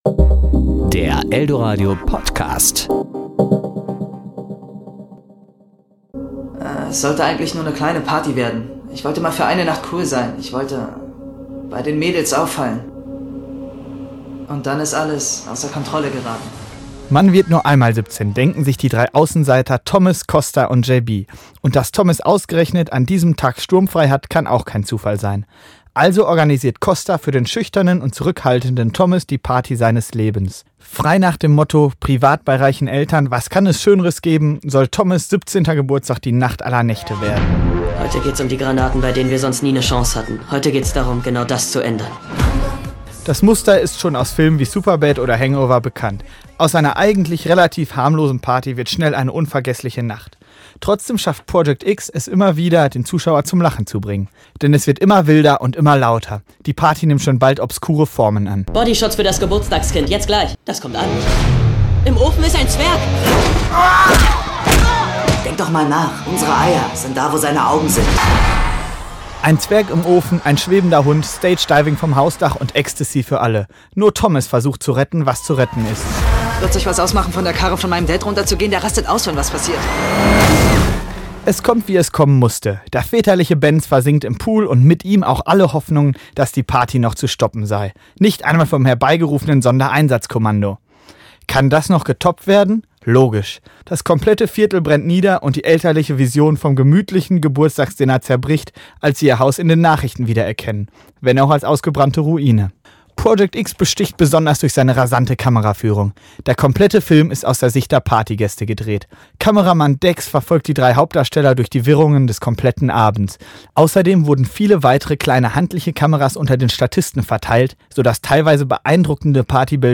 Kinorezension Project X